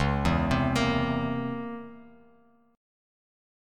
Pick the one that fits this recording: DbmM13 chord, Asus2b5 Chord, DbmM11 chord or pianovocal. DbmM13 chord